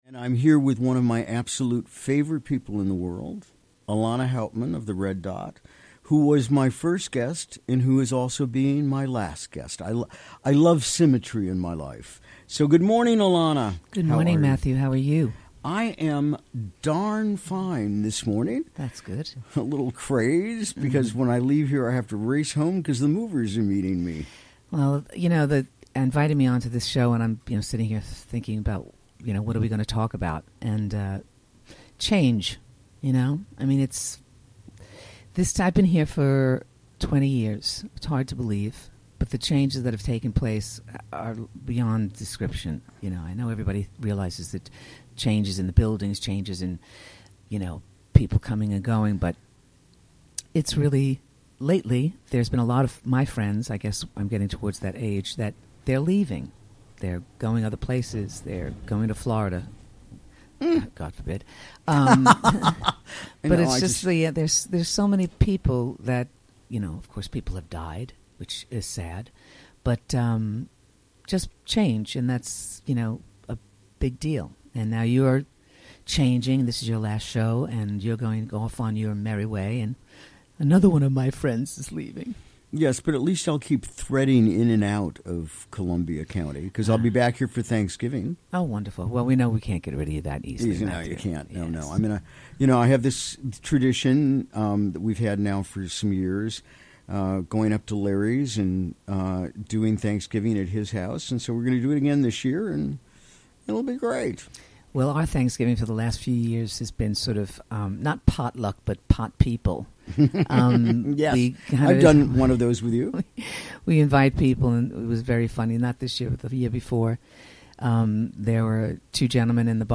Recorded live during the WGXC Morning Show on Wednesday, March 28, 2018.